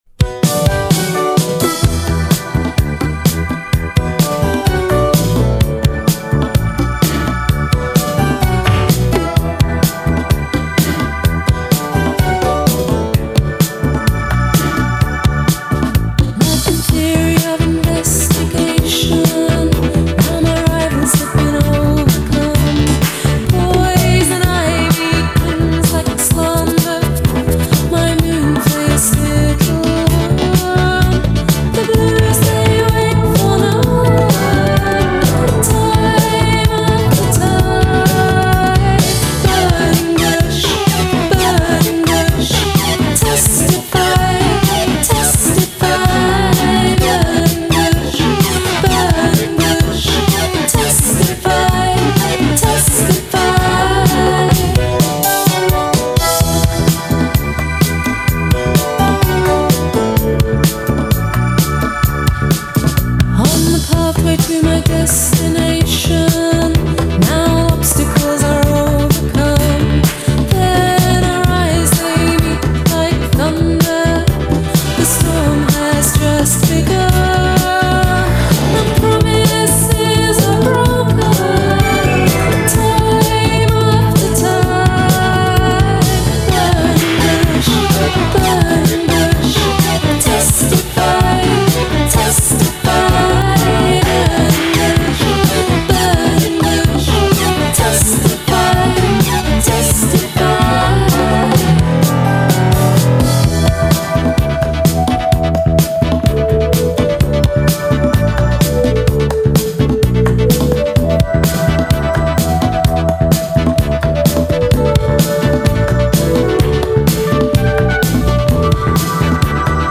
Genre: Synthpop.